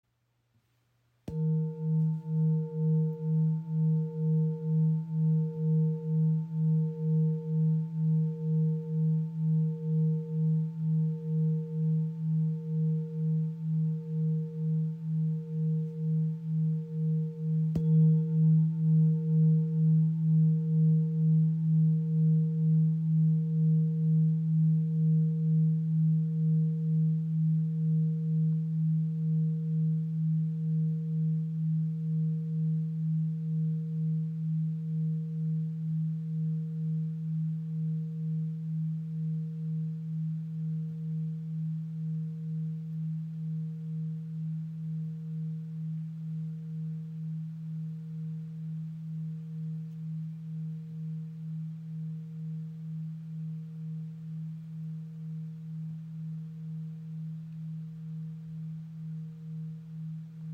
Klangschale mit Shri Yantra | ø 24 cm | Ton ~ E | Sonnenton ( ca. 160 Hz)
Handgefertigte Klangschale aus Kathmandu
• Icon Inklusive passendem rotem Filzschlägel
• Icon Zentrierender Klang im Ton ~ E | Planetenton Sonnenton (160 Hz) | 1493 g.
Ihr obertonreicher Klang im Ton E ist klar und zentrierend.